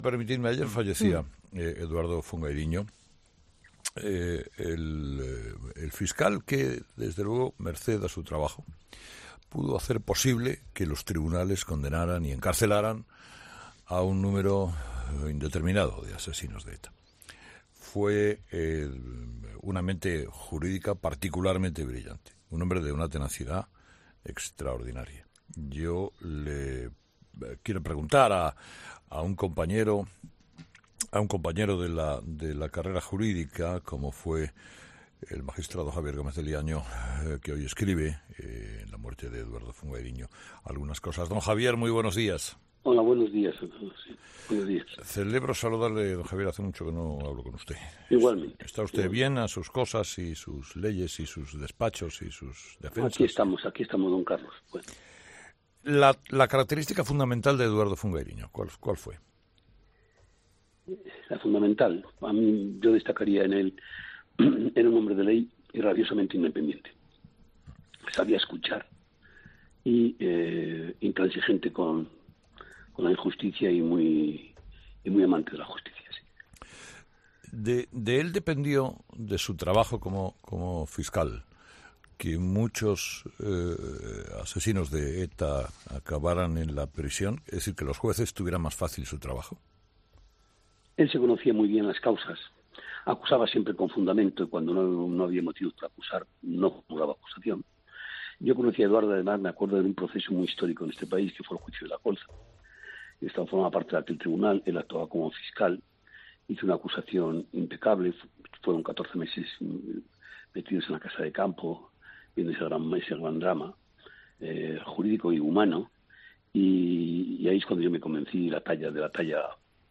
Una "mente jurídica particularmente brillante y de tenacidad extraordinaria”, según ha recordado Carlos Herrera durante la entrevista al magistrado, Javier Gómez de Liaño quien a su vez se ha referido al exfiscal como “un hombre de ley rabiosamente independiente”.